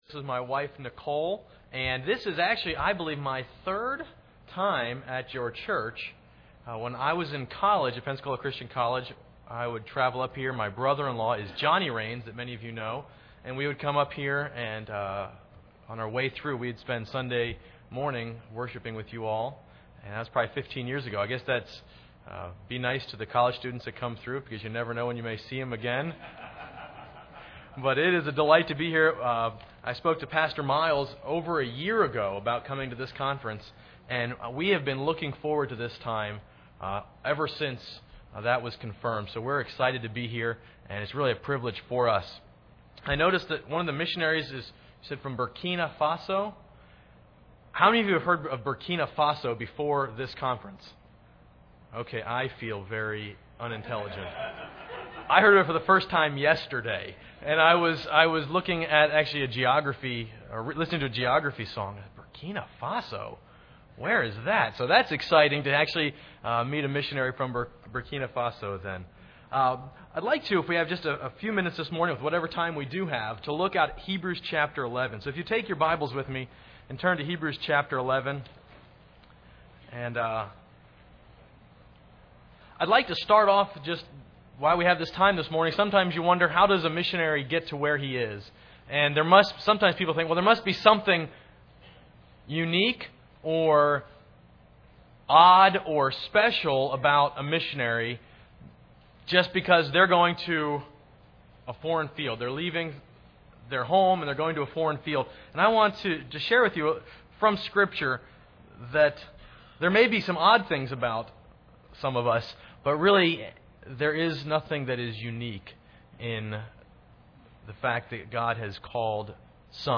Service Type: Sunday School Hour